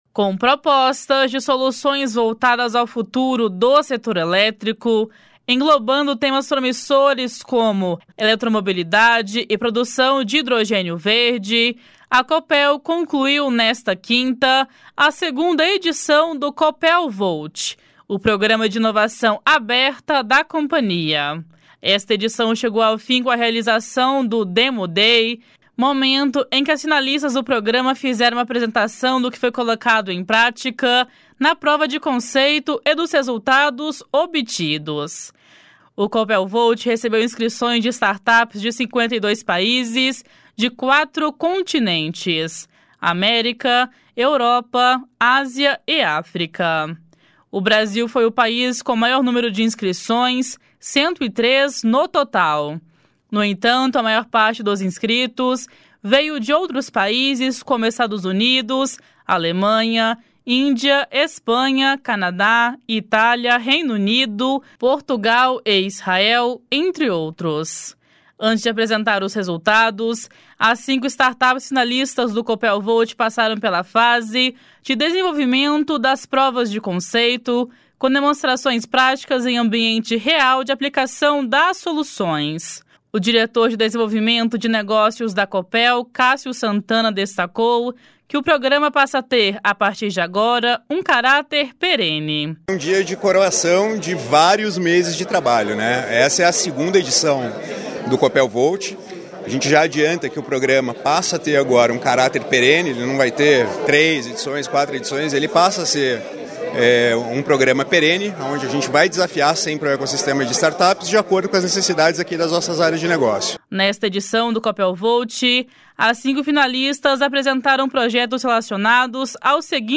Nesta edição do Copel Volt, as cinco finalistas apresentaram projetos relacionados aos seguintes temas: digitalização e melhorias em gestão e processos, gestão de ativos e instalações, relacionamento com clientes e soluções em serviços, eletromobilidade e redes inteligentes, e hidrogênio verde, armazenamento de energia e demais energias limpas. (Repórter